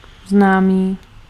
Ääntäminen
Vaihtoehtoiset kirjoitusmuodot (vanhentunut) acquaintaunce Synonyymit knowledge familiarity fellowship intimacy friend Ääntäminen US : IPA : [ʌˈkwen.tɛns] RP : IPA : /əˈkweɪntəns/ US : IPA : /ʌˈkweɪn.təns/